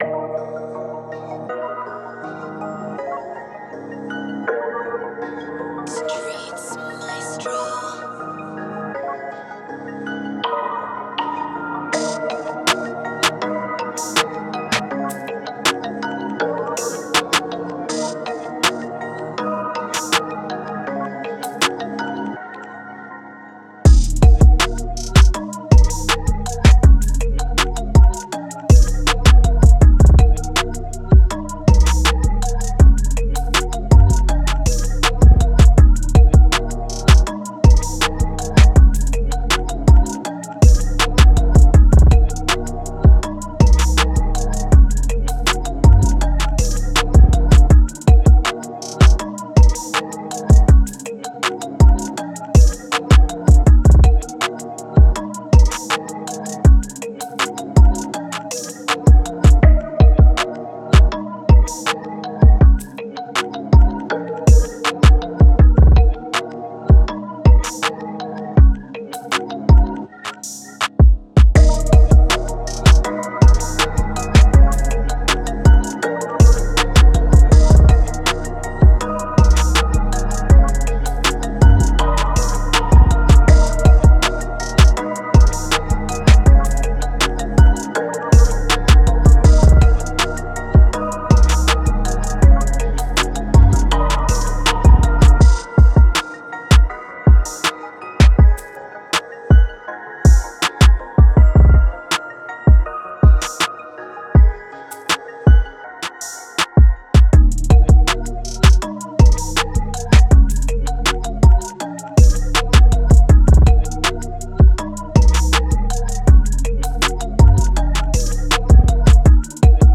Bouncy Type Beat
Moods: bouncy, hypnotic, melodic
Genre: Rap
Tempo: 161